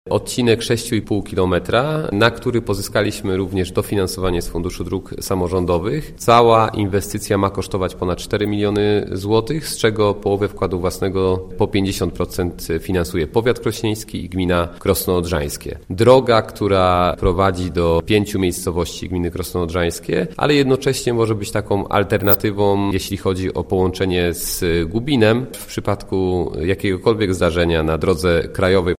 – Tak jest w naszym przypadku, ale tak się składa, że to jedna z najważniejszych dróg w powiecie, swoista obwodnica drogi krajowej. A chodzi o drogę Krosno-Retno, w kierunku Wężysk – informuje Grzegorz Garczyński, starosta krośnieński.